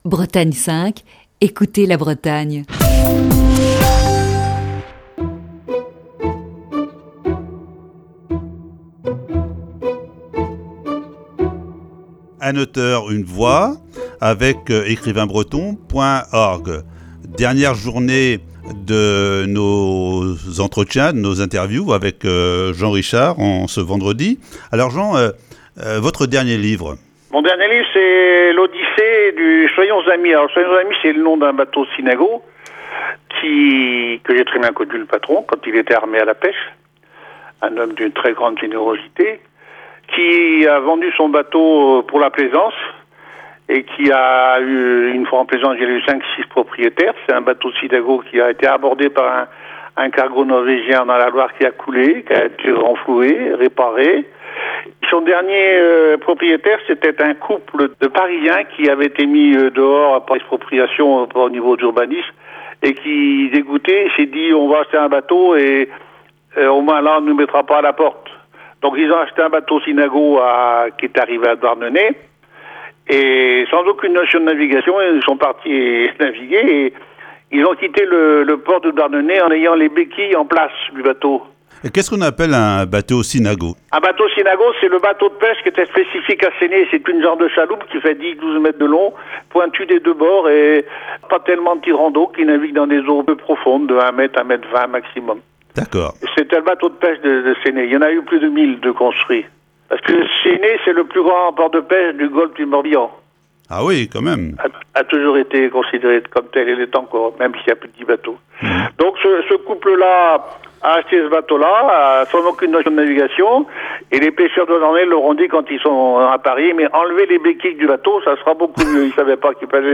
Voici ce vendredi la cinquième et dernière partie de cette série d'entretiens.